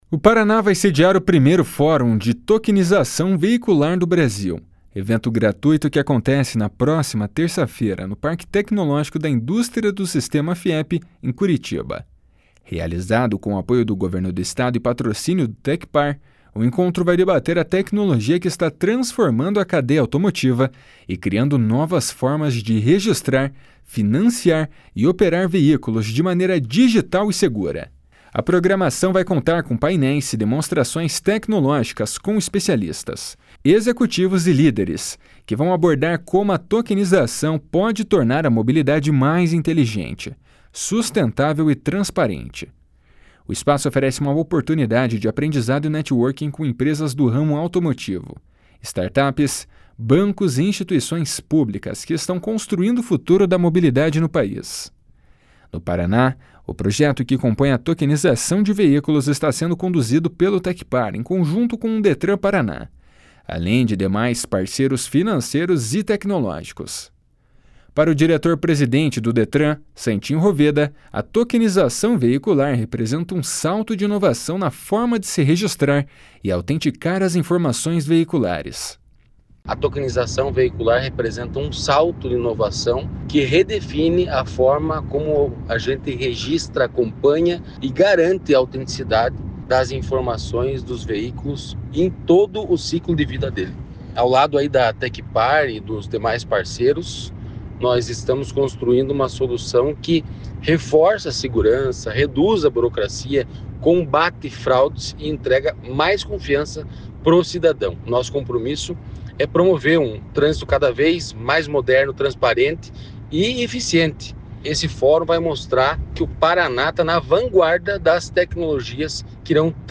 Para o diretor-presidente do Detran, Santin Roveda, a tokenização veicular representa um salto de inovação na forma de se registrar e autenticar as informações veiculares. // SONORA SANTIN ROVEDA //